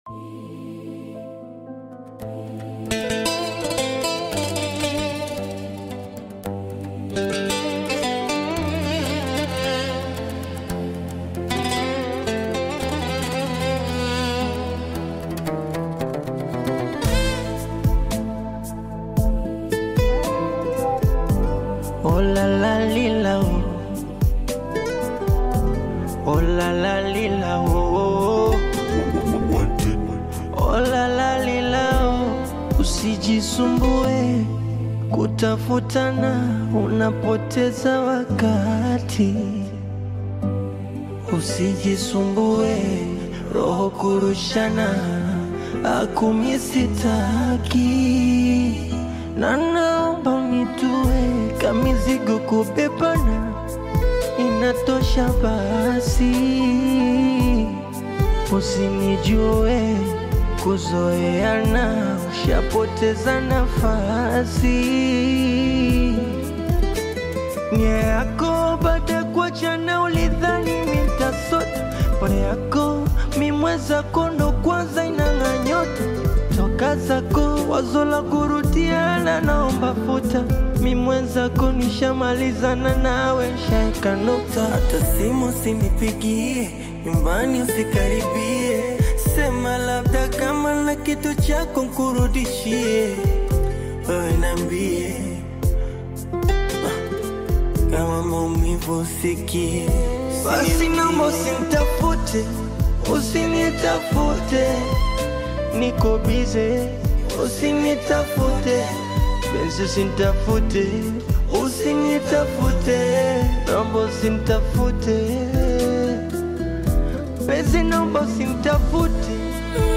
smooth Bongo Flava/Afro-Pop collaboration
expressive vocals
polished Afro-inspired production